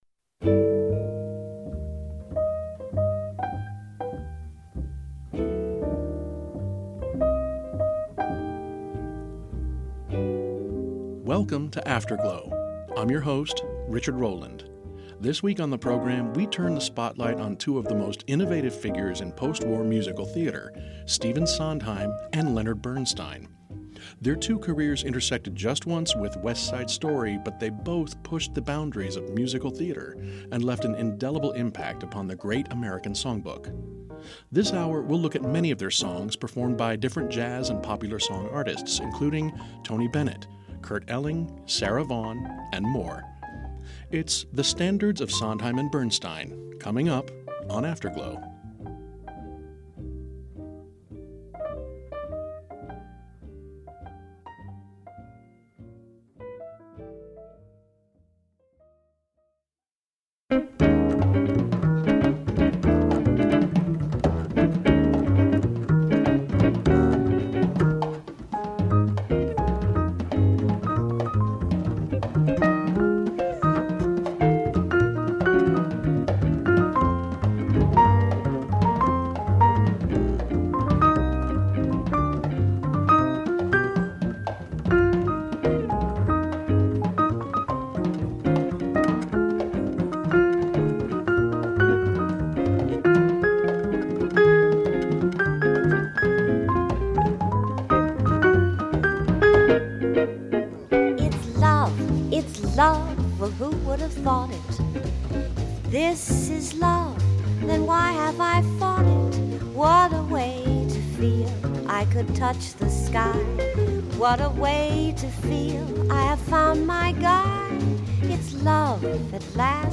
jazz standards